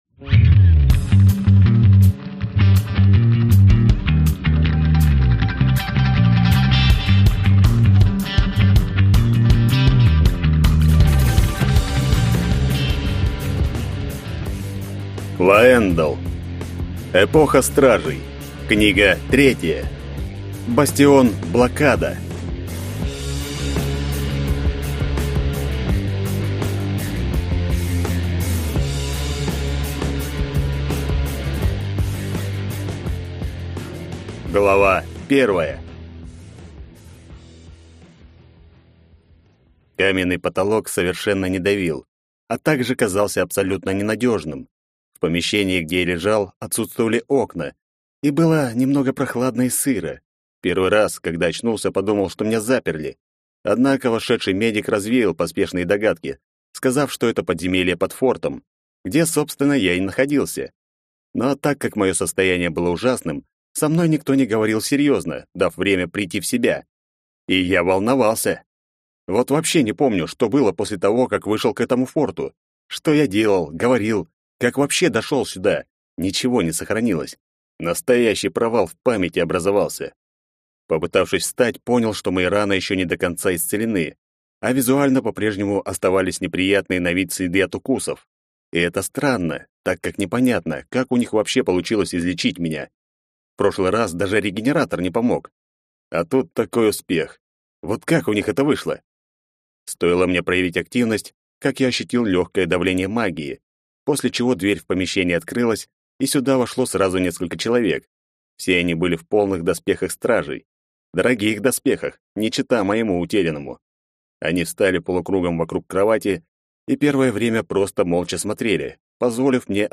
Аудиокнига Бастион «Блокада» | Библиотека аудиокниг